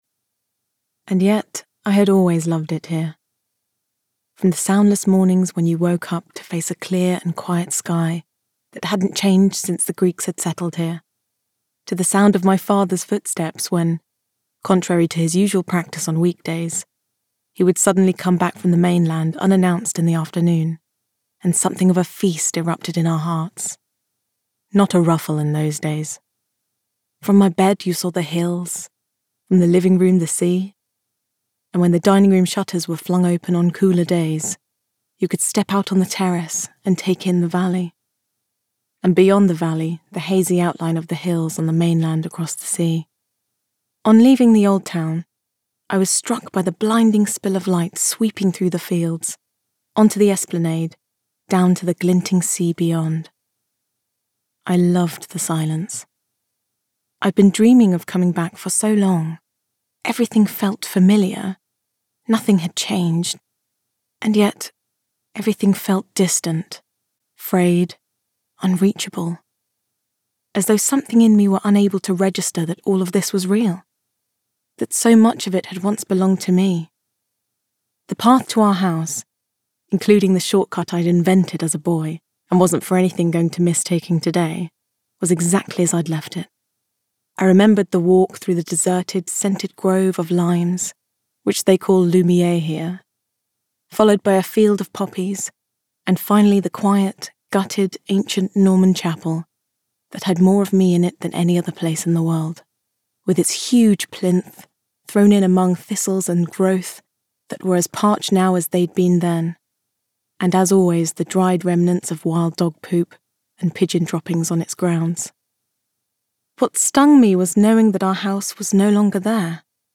Voice Reel
Audiobook